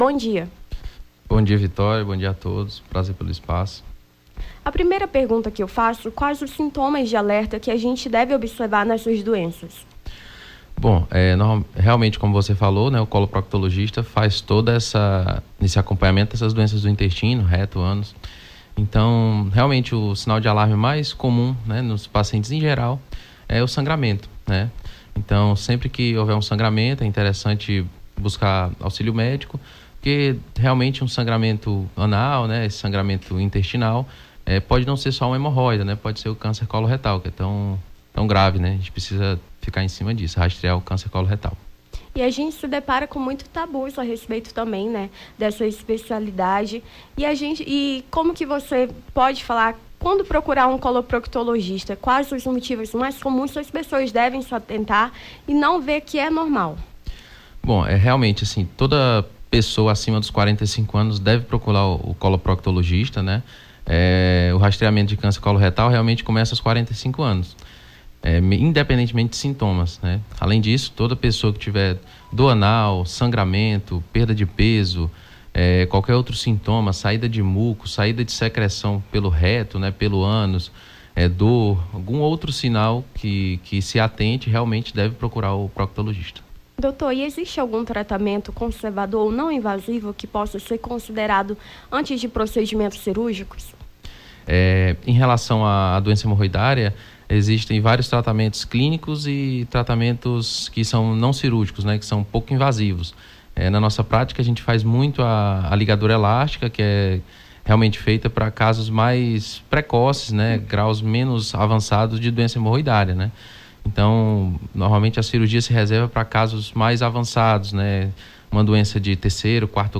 Nome do Artista - CENSURA - ENTREVISTA (BOM DIA DOUTOR) 10-08-23.mp3